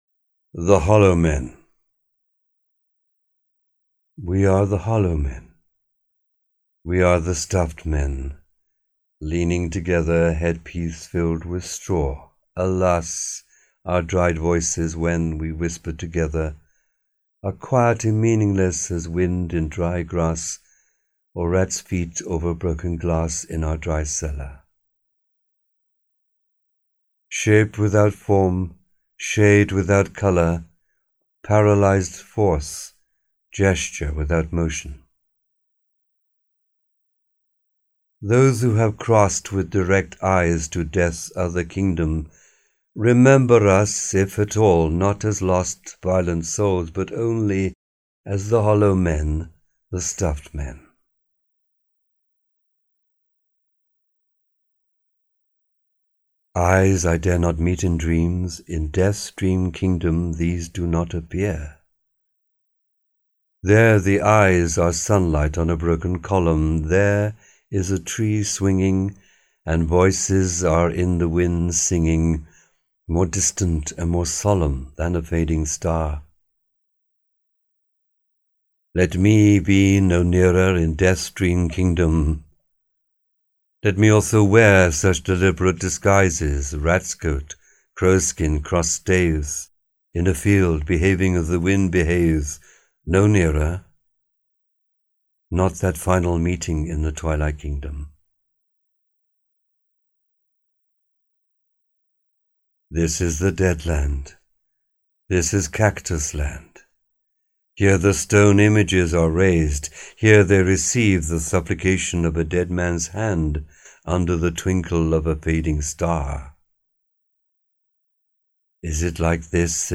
POETRY READINGS